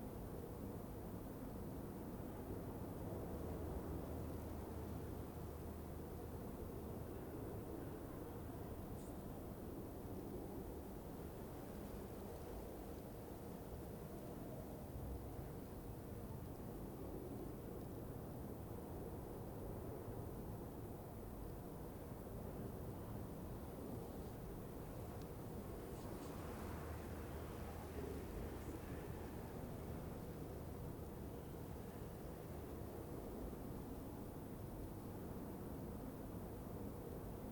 Minecraft Version Minecraft Version latest Latest Release | Latest Snapshot latest / assets / minecraft / sounds / ambient / nether / soulsand_valley / ambience.ogg Compare With Compare With Latest Release | Latest Snapshot
ambience.ogg